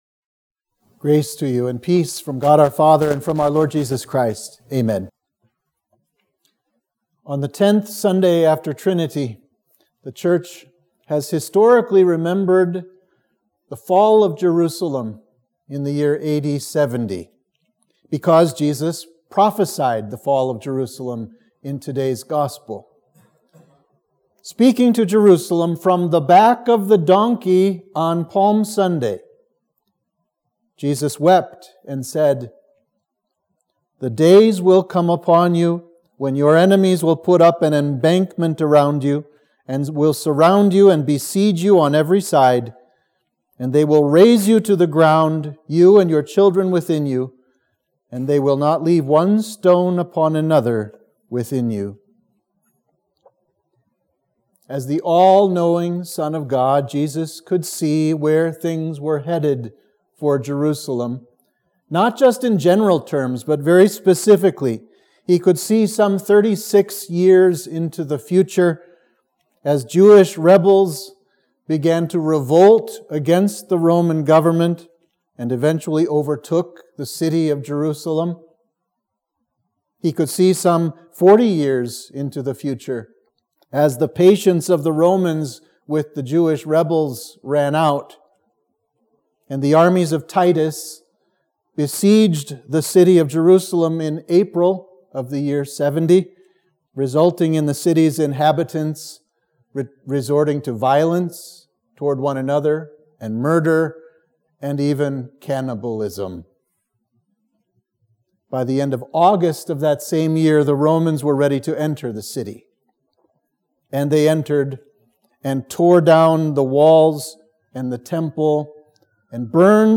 Sermon for Trinity 10